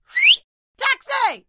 Звуки такси
Свист такси в бессонном городе